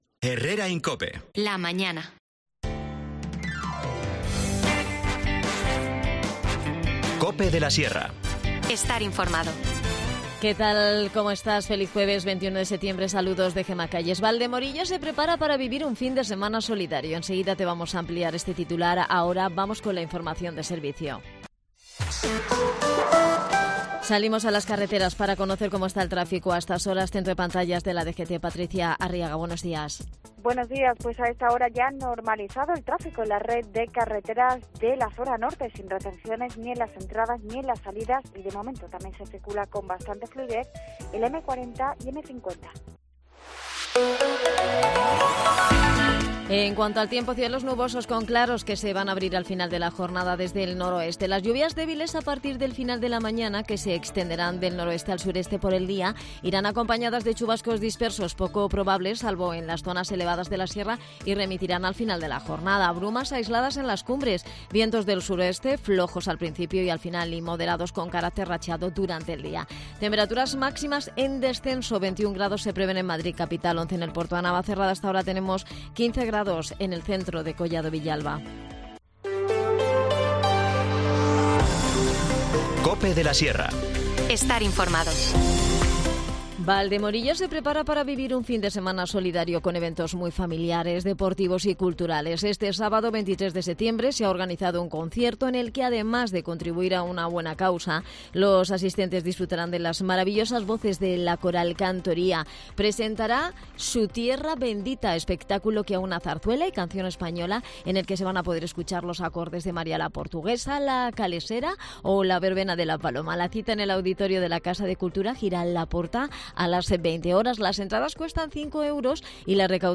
Joel Dalda, concejal de Deportes en Collado Villalba, Capital de la Sierra, nos visita para hablarnos de toda la actualidad del municipio que pasa por la presentación de la nueva Programación de Cultura para este último trimestre del año.